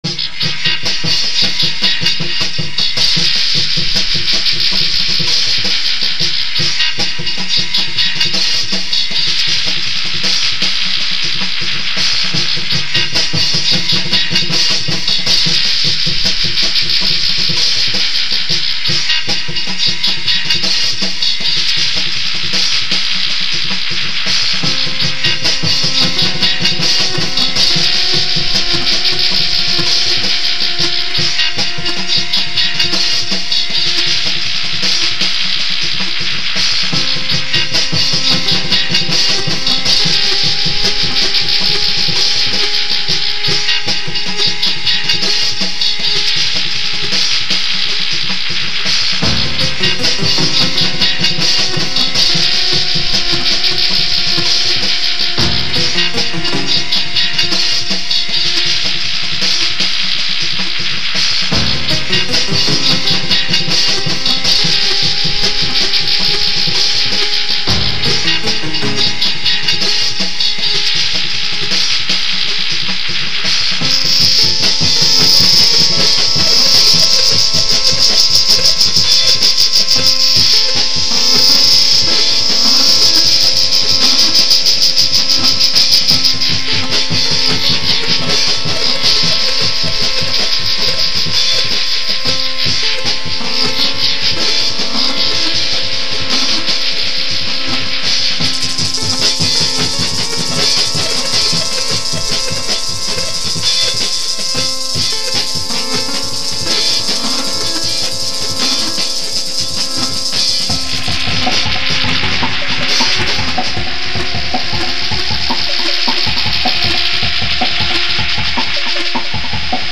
noisejazz